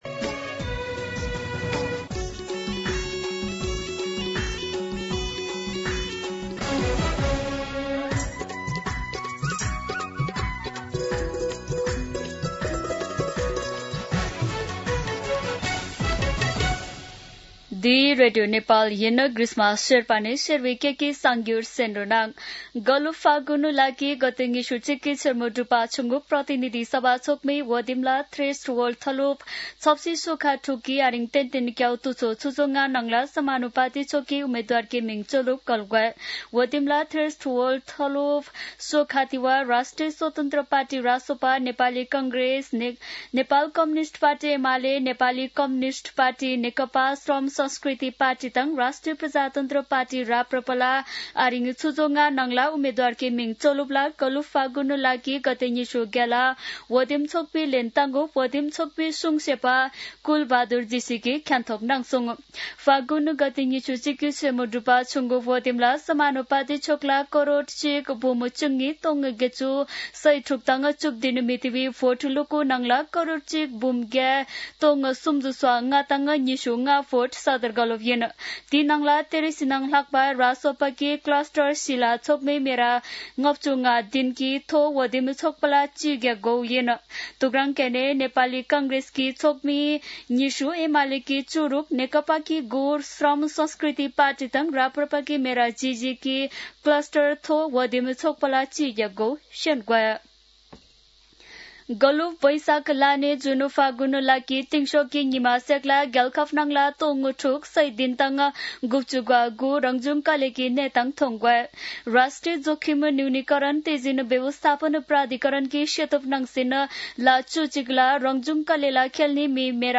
शेर्पा भाषाको समाचार : १ चैत , २०८२